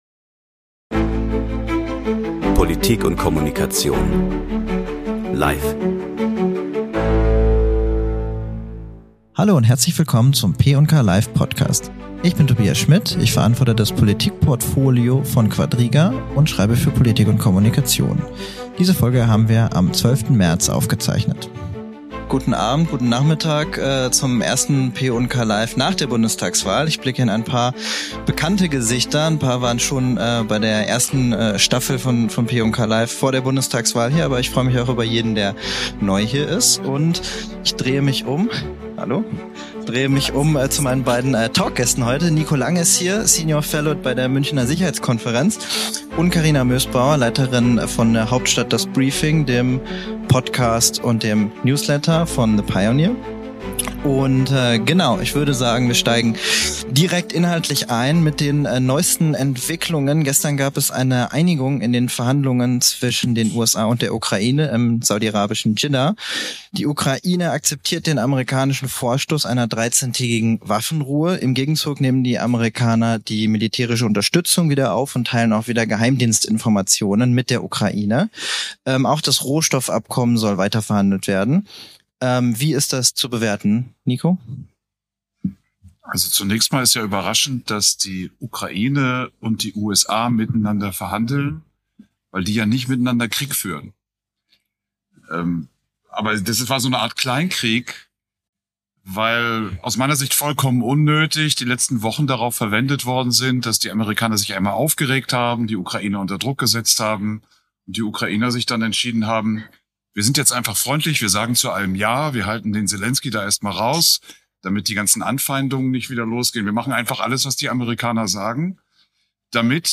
Live-Talk im Quadriga-Café